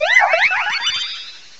sovereignx/sound/direct_sound_samples/cries/carbink.aif at master